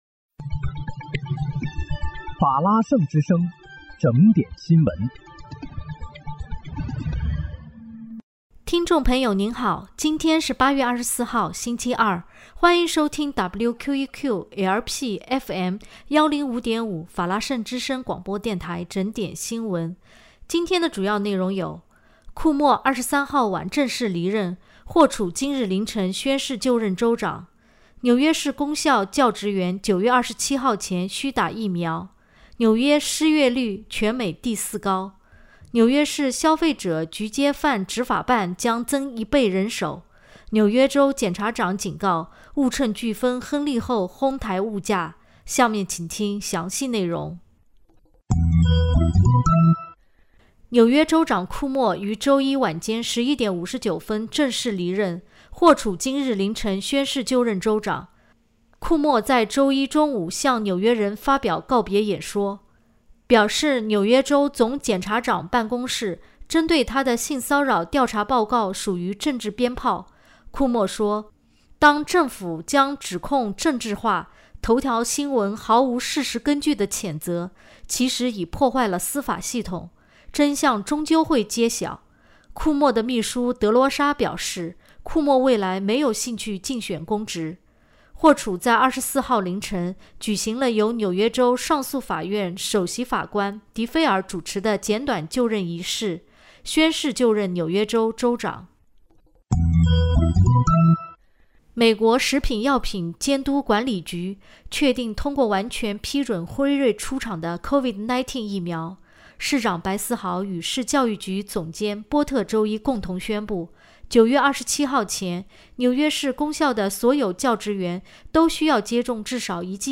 8月24日（星期二）纽约整点新闻